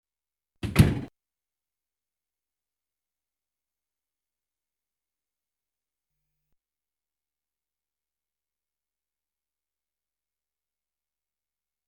Kuehlschranktuer.mp3